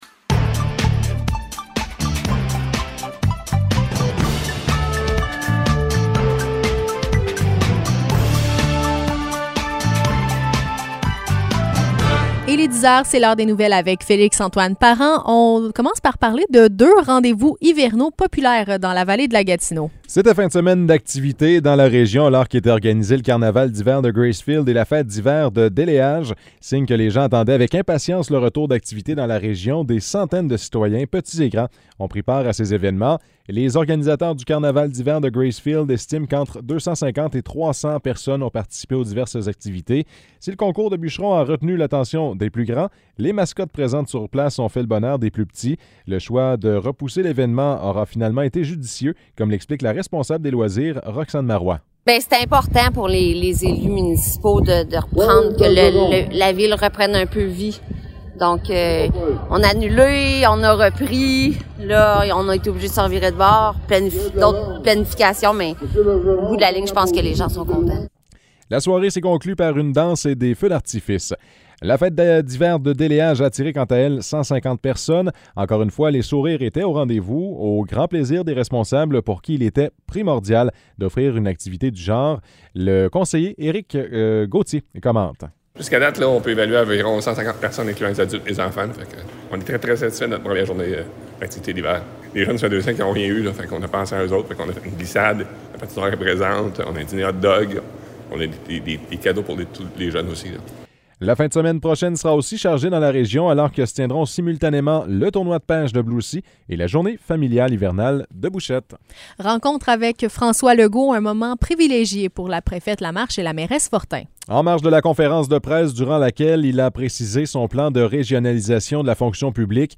Nouvelles locales - 28 février 2022 - 10 h